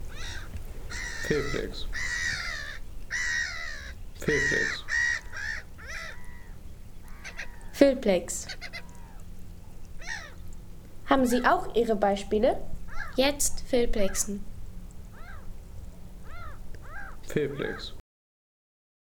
Heringsmöwe im Wattenmeer
Heringsmöwe im Wattenmeer Home Sounds Tierwelt Vögel Heringsmöwe im Wattenmeer Seien Sie der Erste, der dieses Produkt bewertet Artikelnummer: 40 Kategorien: Tierwelt - Vögel Heringsmöwe im Wattenmeer Lade Sound....